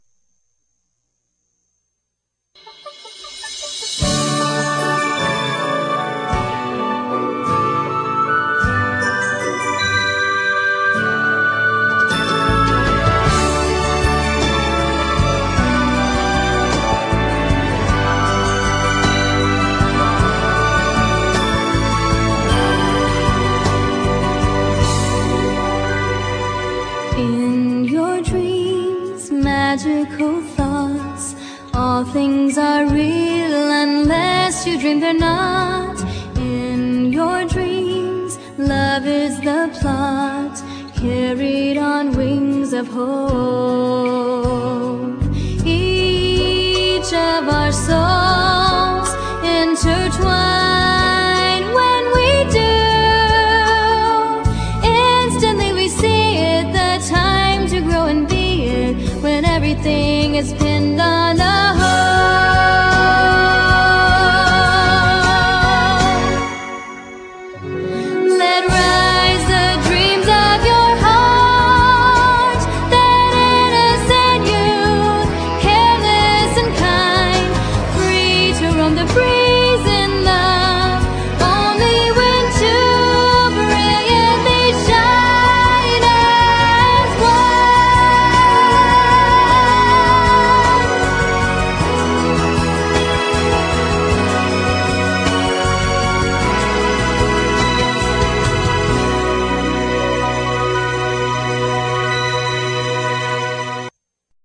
The songs were taken directly from the movies.